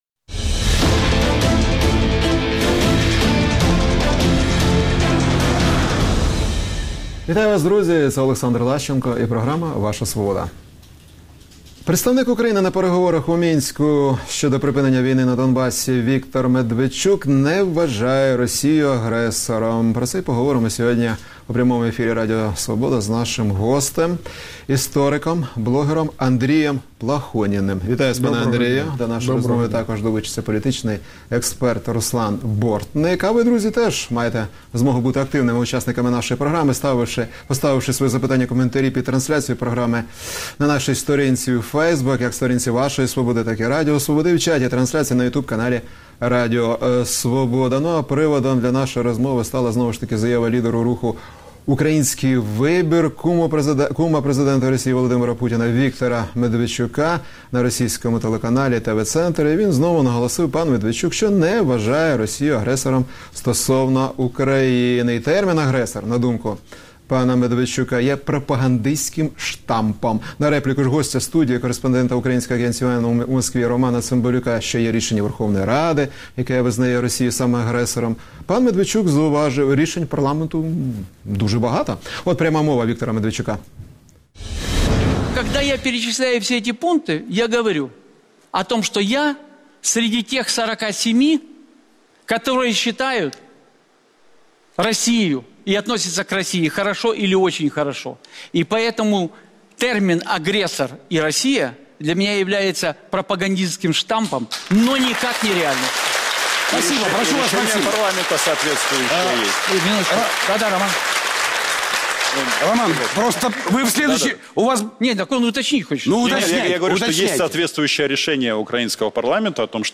політолог
блогер